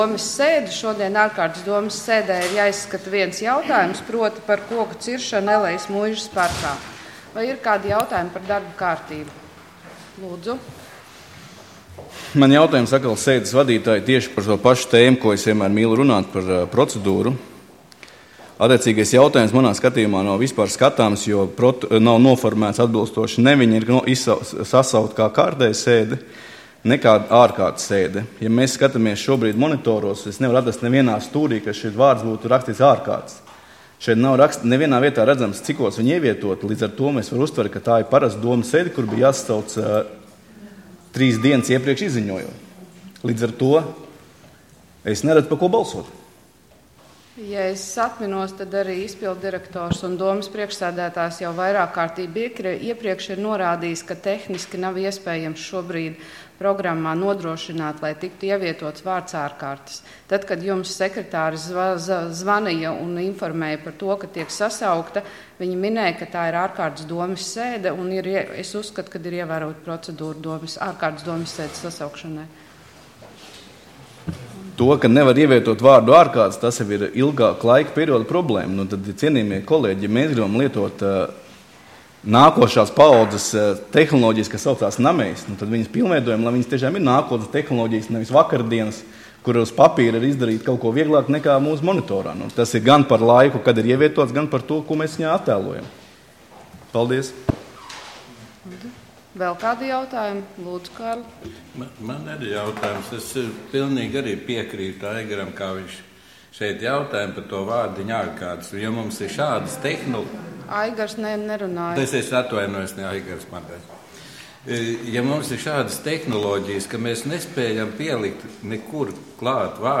Domes ārkārtas sēde Nr. 13